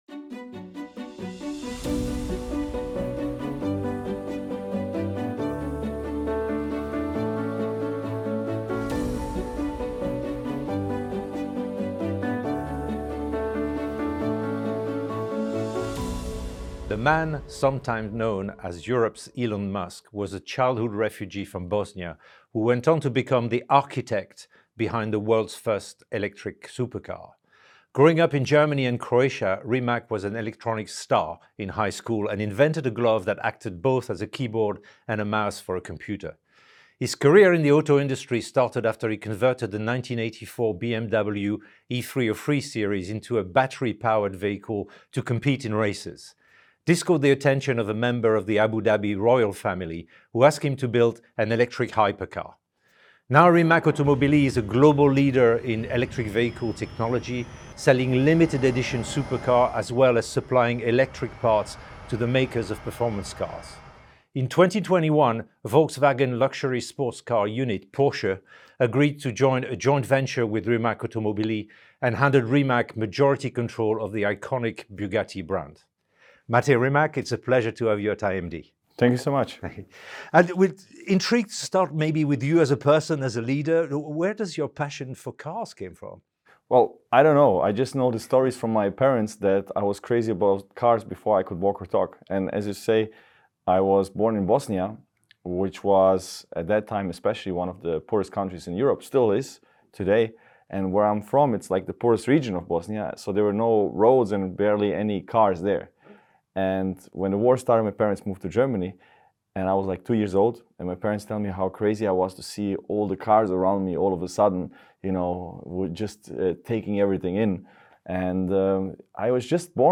The-Interview-with-Mate-Rimac.mp3